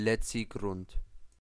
Letzigrund (Swiss Standard German: [ˈlɛtsiɡrʊnd]